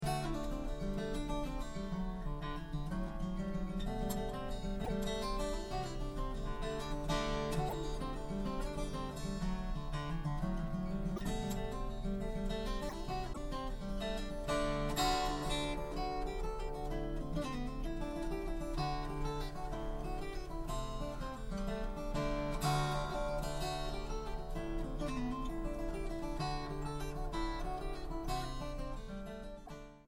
instrumental and vocal folk music of Ireland